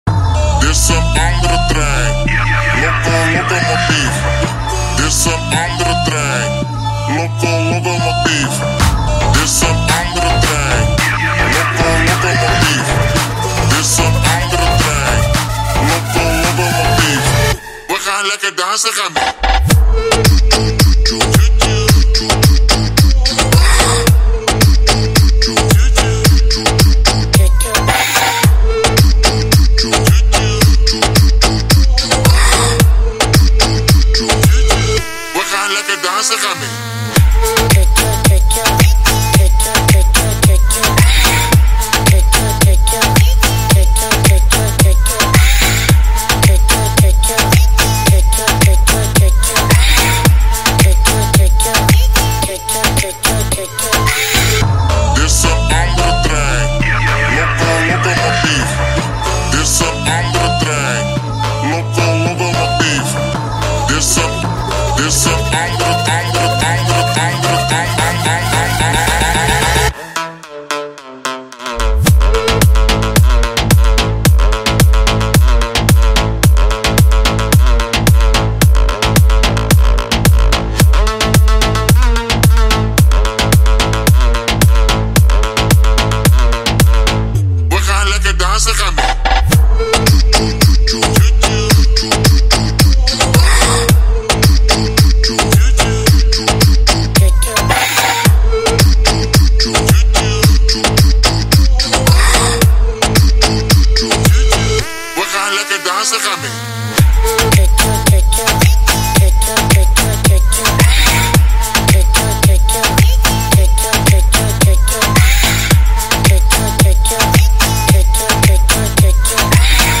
яркая и энергичная композиция